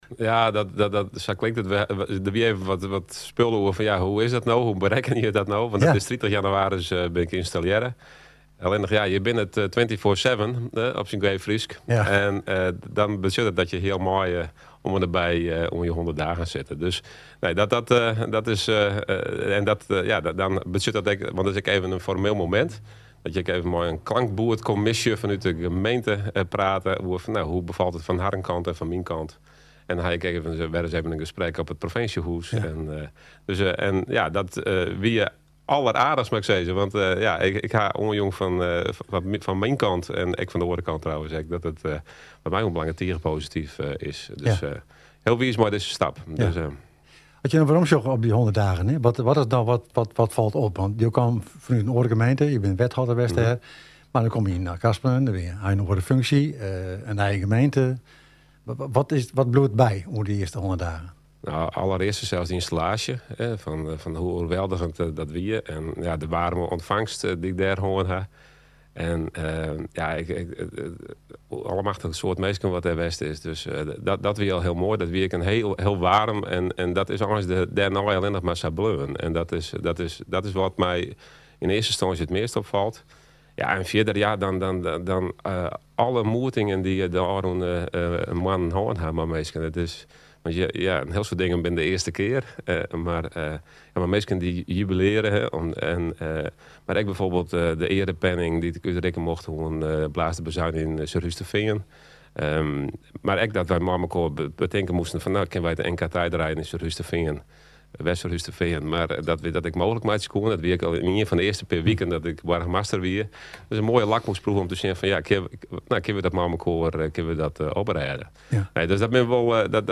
Burgemeester-Achtkarspelen-Jouke-Douwe-de-Vries-te-gast-in-Op-e-Hichte.mp3